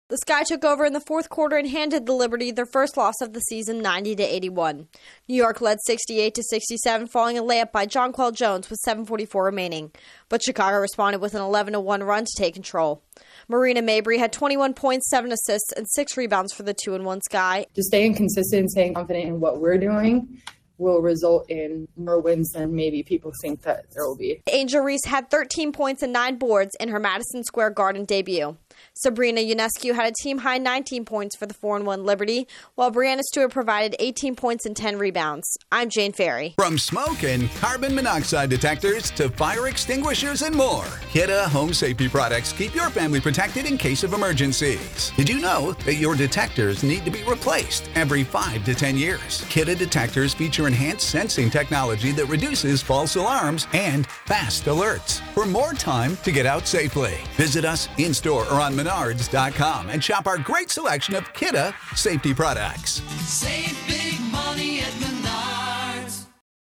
A strong finish lifts the Sky over the Liberty. Correspondent